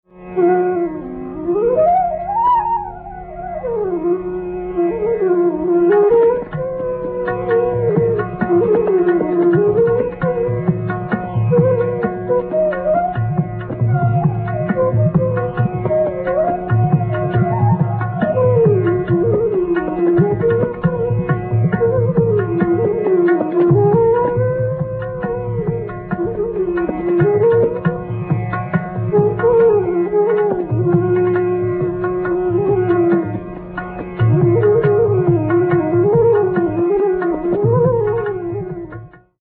Aroha: NRGmMm, GMDNS
Avroh: SNDMm, GRGMGRS